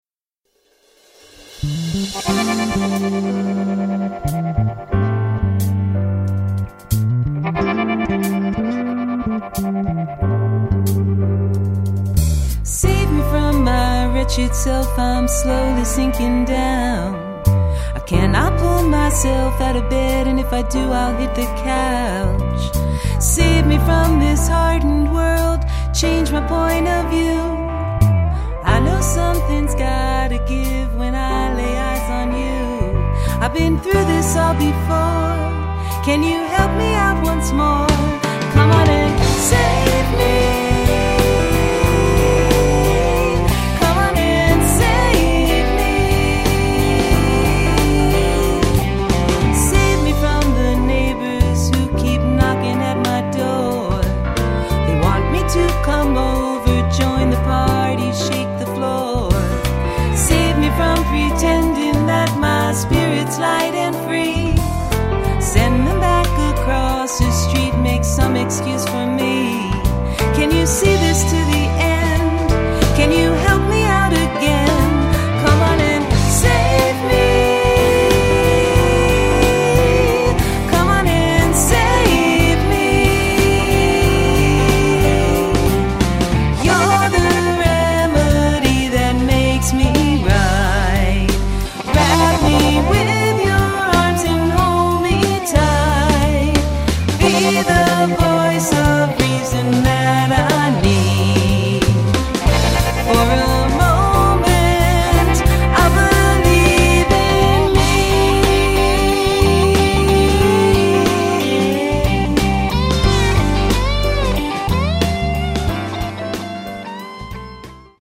(Pop/Jazz)